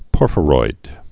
(pôrfə-roid)